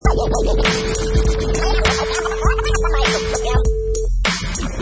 Professional killer vinyl scratch on top of hip hop beat, perfect for sampling, mixing, music production, timed to 100 beats per minute
Product Info: 48k 24bit Stereo
Category: Musical Instruments / Turntables
Try preview above (pink tone added for copyright).
Tags: scratches
Scratch_Crazy_100BT_1.mp3